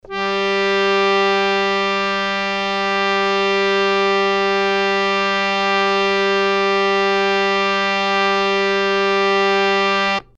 harmonium